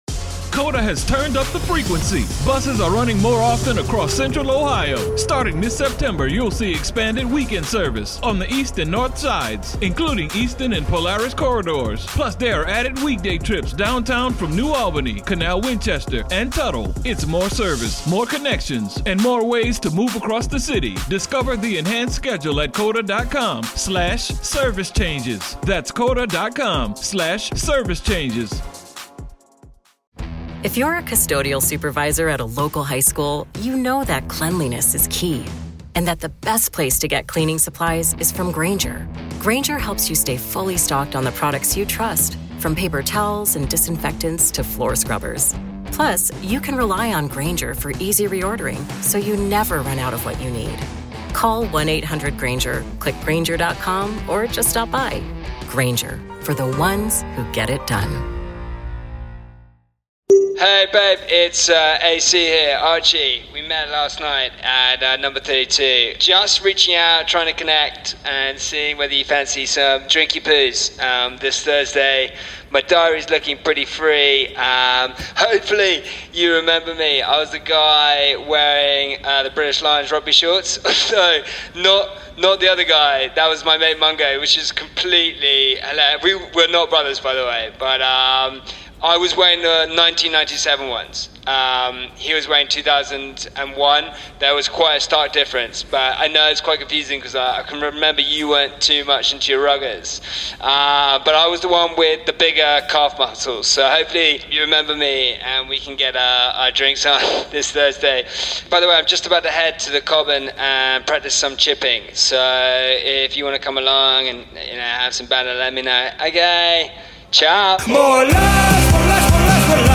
Today we're delighted to have an exclusive interview with the legendary Wasps, England, Lions and latterly Buffalo Bills superstar Mr Christian Wade.